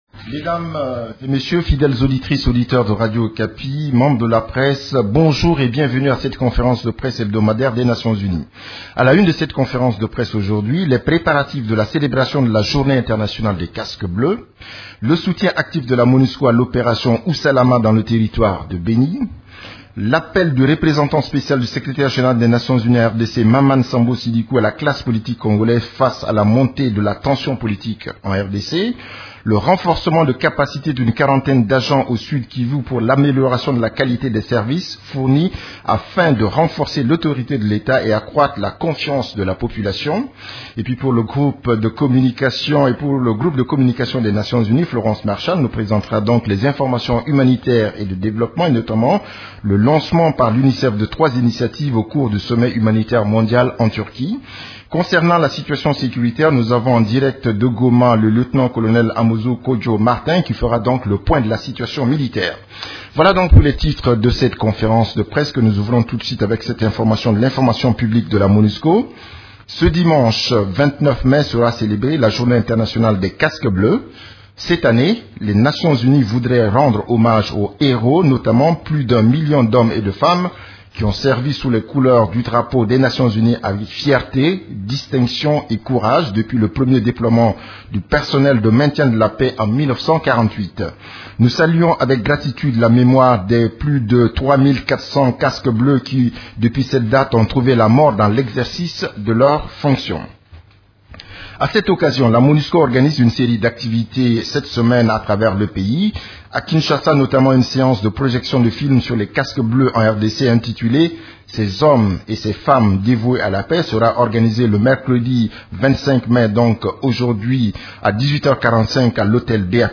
Conférence de presse du 25 mai 2016
La conférence de presse hebdomadaire des Nations unies du mercredi 25 mai à Kinshasa a porté sur les activités des composantes de la Monusco, les activités de l’équipe-pays et la situation militaire.
Vous pouvez écouter la première partie de la conférence de presse: